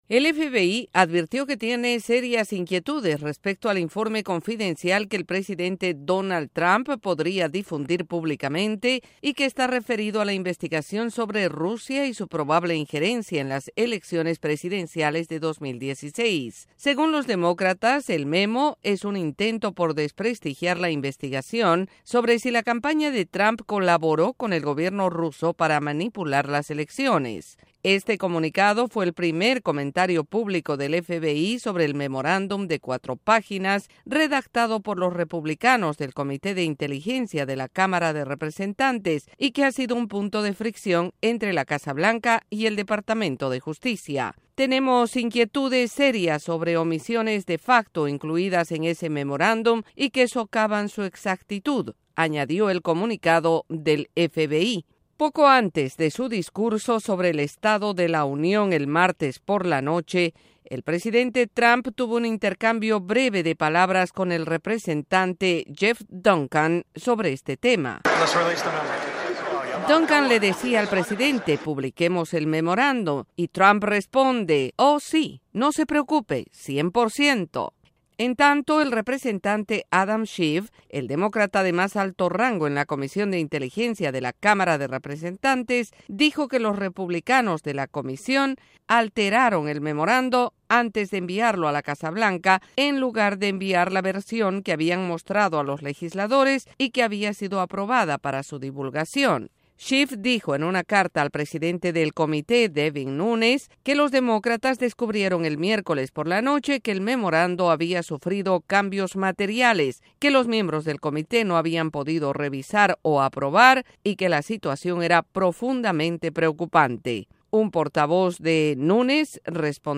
Un documento confidencial referido a la investigación de la probable injerencia rusa en las elecciones presidenciales 2016 envuelve en controversia a la Casa Blanca, el FBI y miembros del Congreso estadounidense. Desde la Voz de América en Washington DC informa